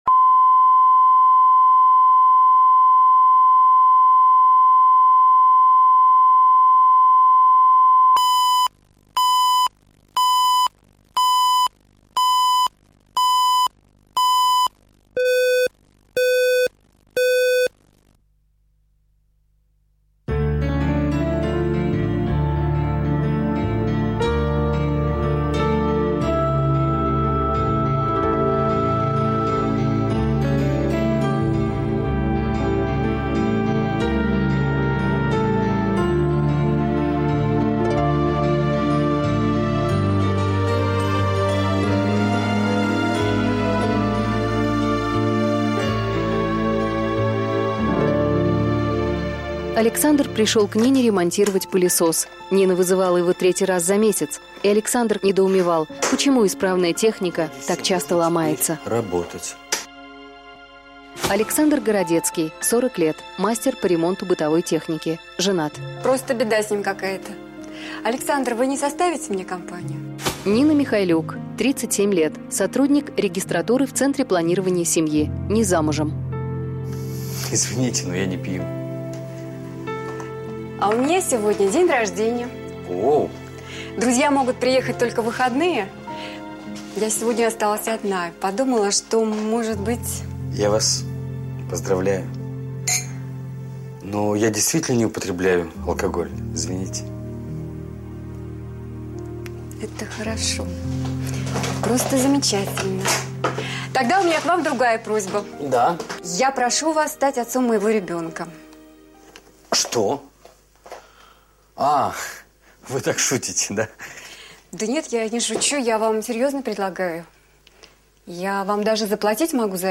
Аудиокнига Сделай мне ребенка | Библиотека аудиокниг